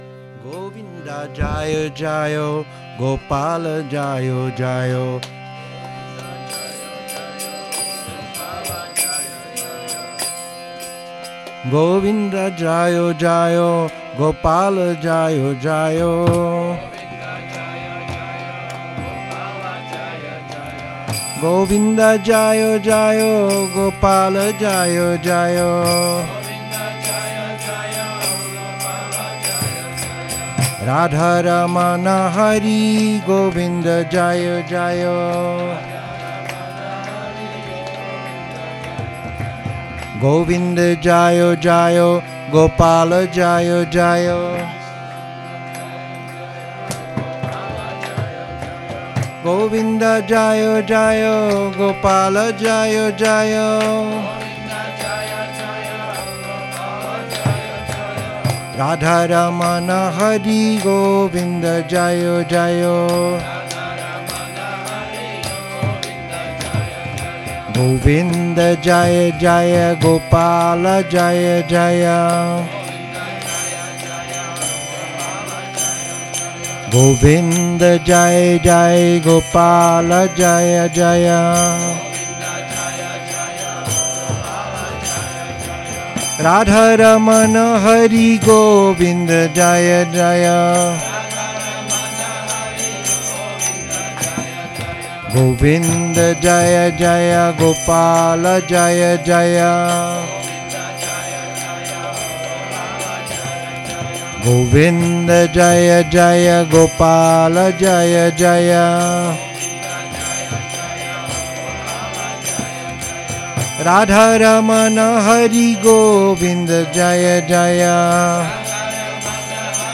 Šrí Šrí Nitái Navadvípačandra mandir
Kírtan